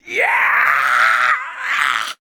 PRESS.wav